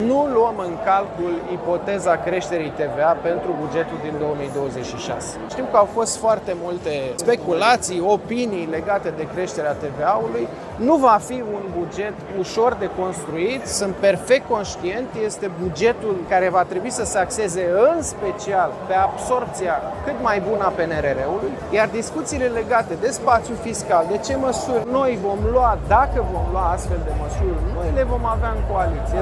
Ipoteza creşterii TVA NU este luată în calcul pentru bugetul pentru 2026, iar eventuala adoptare a altor măsuri va fi discutată în coaliţie, a declarat, astăzi, ministrul Finanţelor, Alexandru Nazare, la Summitul de Fiscalitate şi Tehnologie.